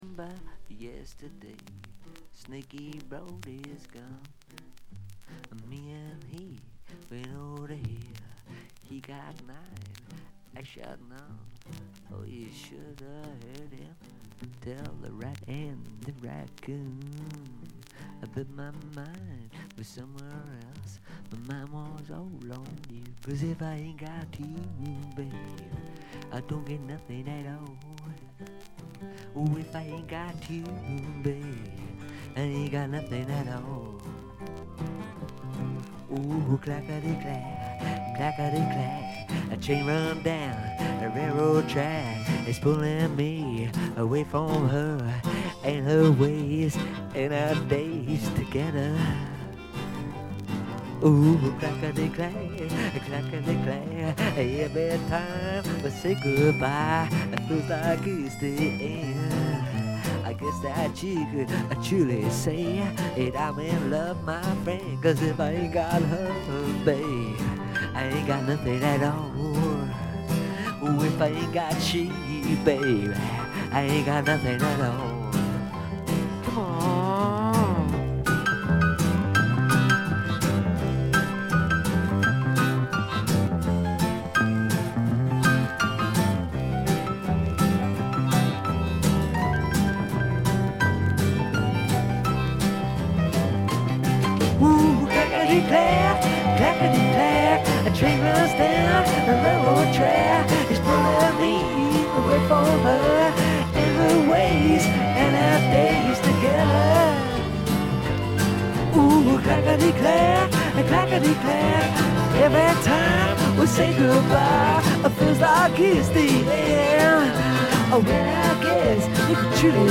バックグラウンドノイズ、チリプチ多め大きめですが、静音部（特にA3序盤あたり）以外では気にならないレベルと思います。
試聴曲は現品からの取り込み音源です。